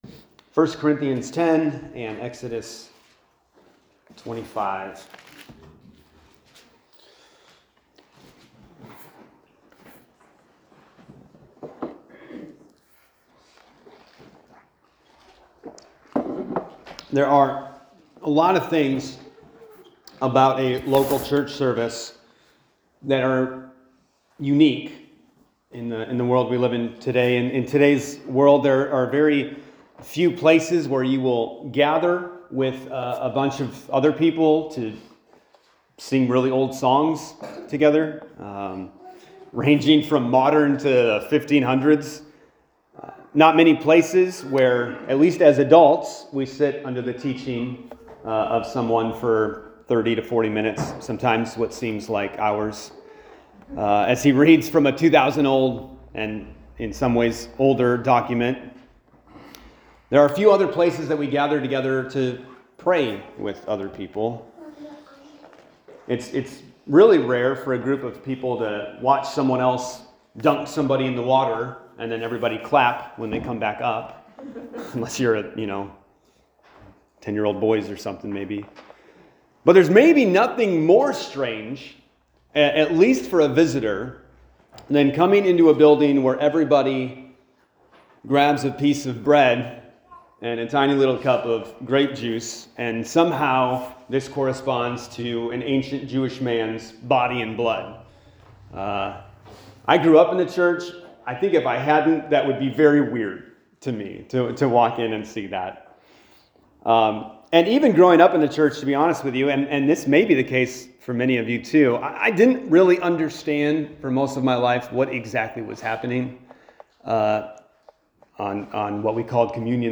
Sermons | Community Church of Hokah